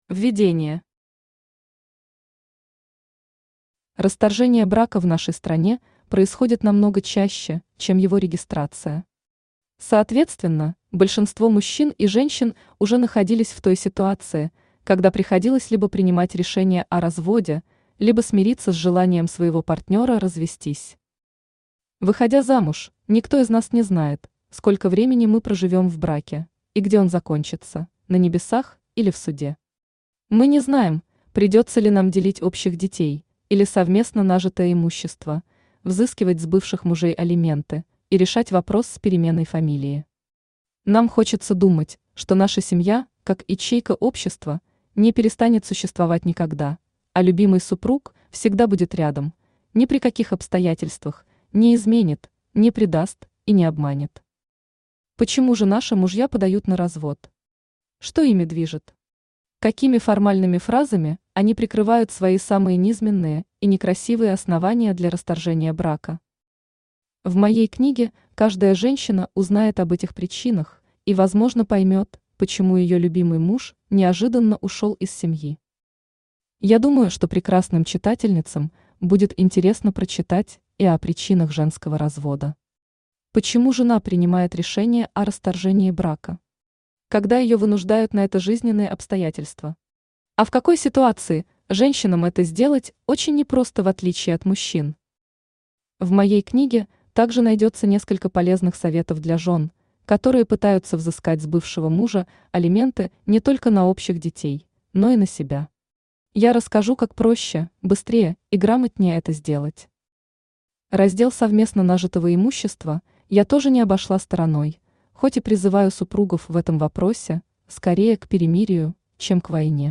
Аудиокнига Как стать счастливой без мужчины? Новая жизнь после развода | Библиотека аудиокниг
Новая жизнь после развода Автор Евгения Сергеевна Макарова Читает аудиокнигу Авточтец ЛитРес.